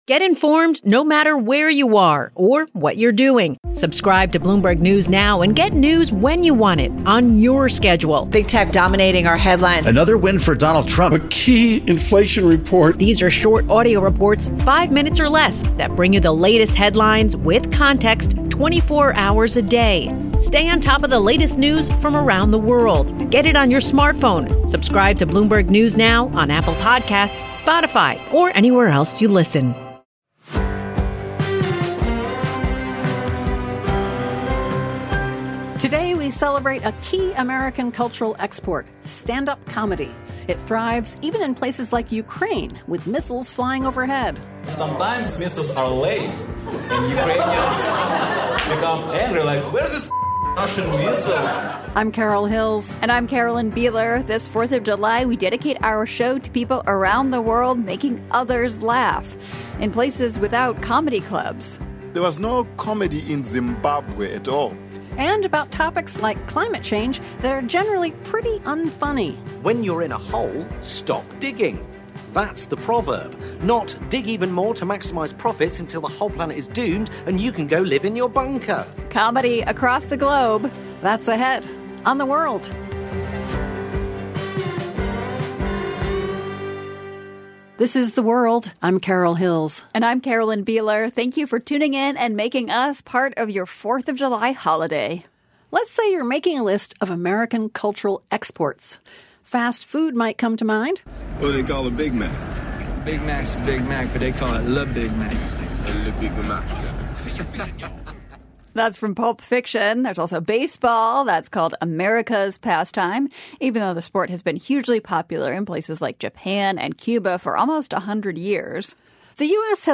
Come have a good laugh during our special comedy show.
Also, Syrian comedians tell jokes that were unthinkable under the former regime in their country.